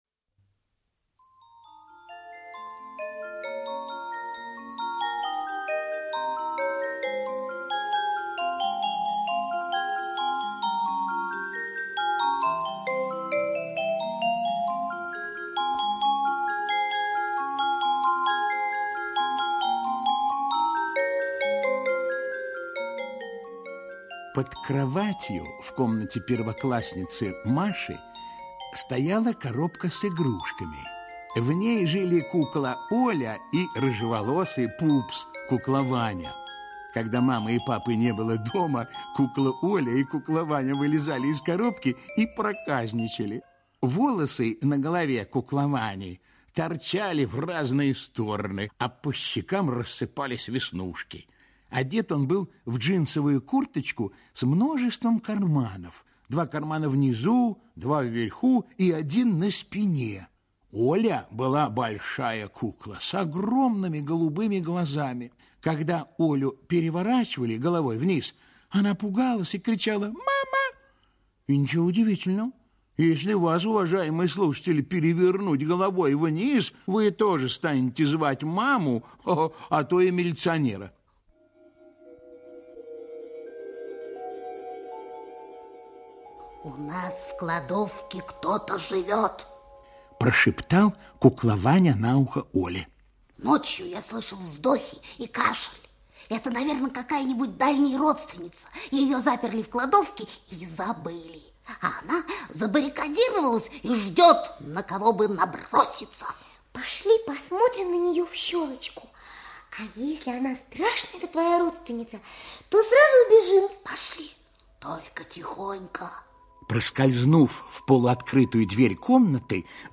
Дракончик Пыхалка - аудиосказка Емеца - слушать онлайн